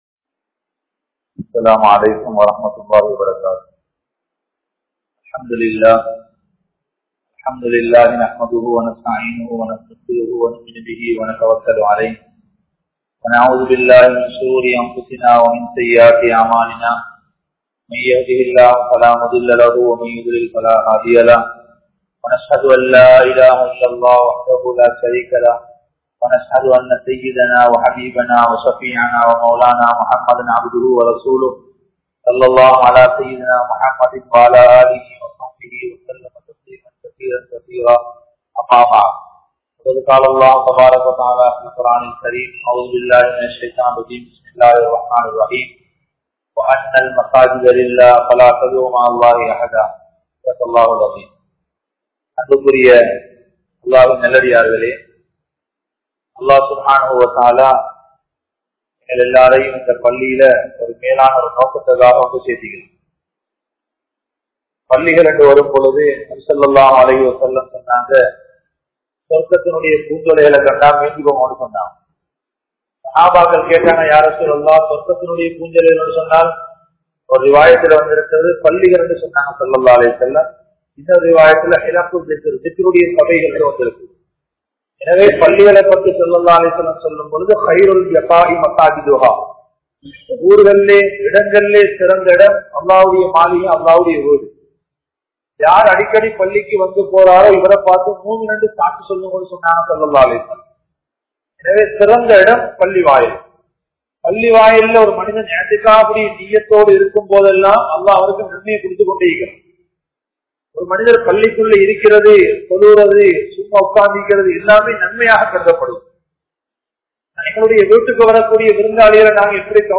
Masjithin Niruvaaha Safaikku Thahuthiyaanavrhal Yaar?(பள்ளிவாசலின் நிருவாக சபைக்கு தகுதியானவர்கள் யார்?) | Audio Bayans | All Ceylon Muslim Youth Community | Addalaichenai